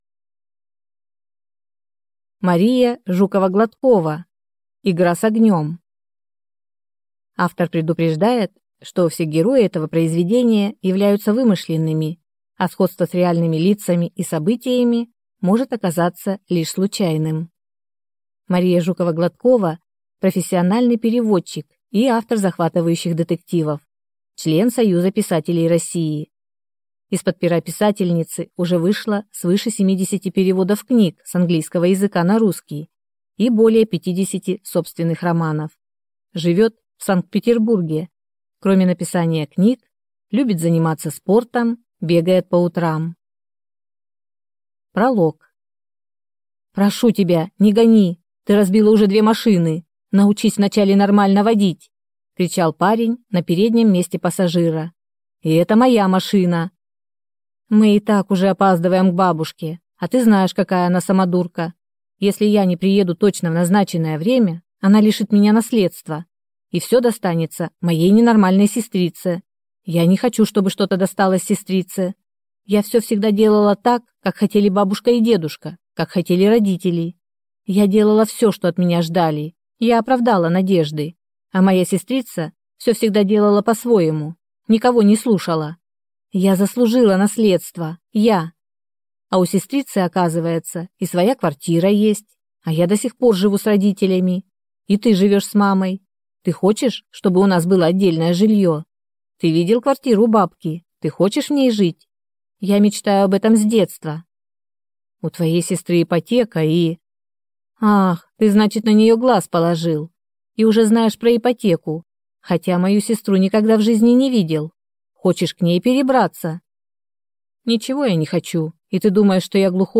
Аудиокнига Игра с огнем | Библиотека аудиокниг